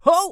traf_oops4.wav